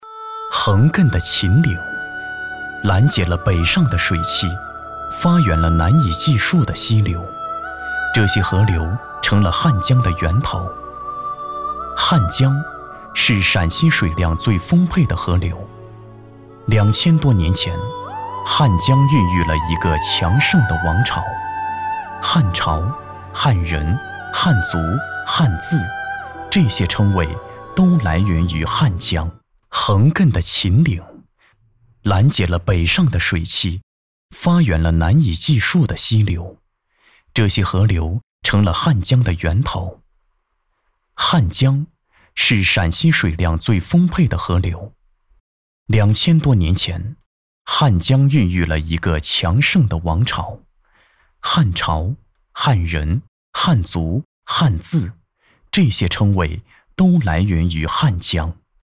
纪录片-男30-深邃感-航拍中国.mp3